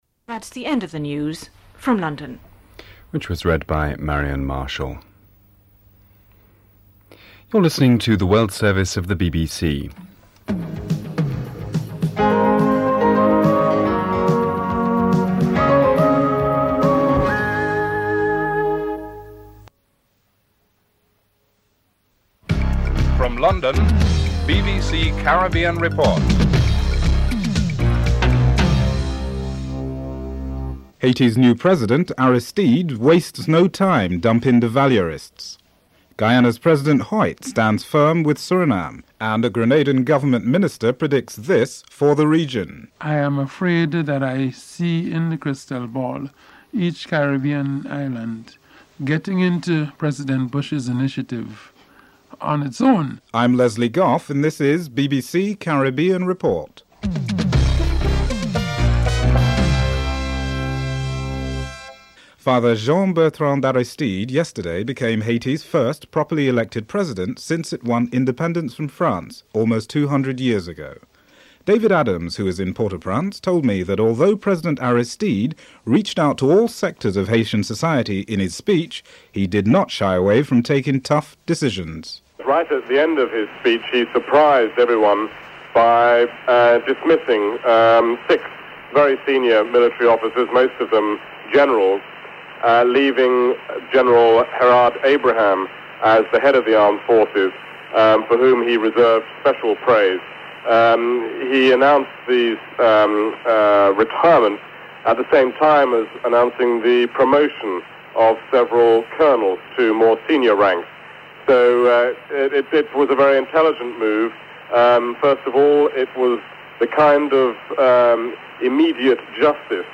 dc.description.tableofcontents1. Headlines (00:00-01:02)en_US
dc.formatStereo 192 bit rate MP3;44,100 Mega bits;16 biten_US
dc.typeRecording, oralen_US